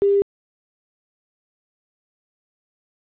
callwaiting_nz.wav